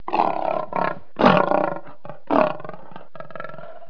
جلوه های صوتی
دانلود صدای حیوانات جنگلی 109 از ساعد نیوز با لینک مستقیم و کیفیت بالا